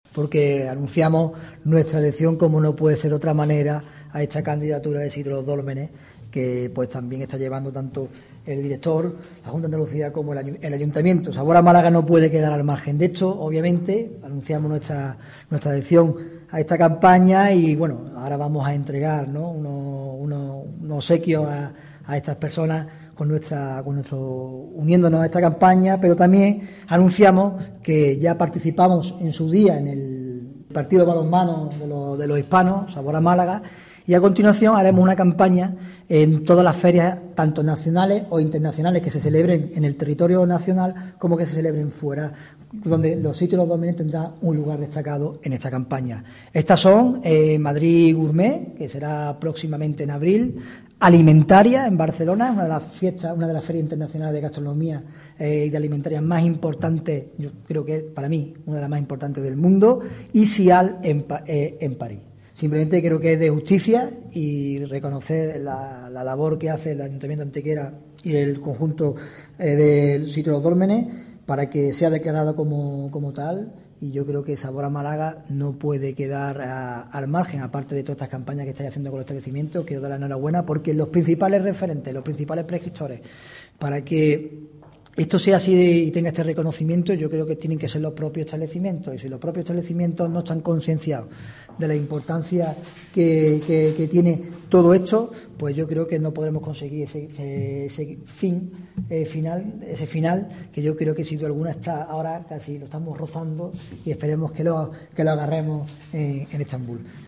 Cortes de voz
J. Florido   735.34 kb  Formato:  mp3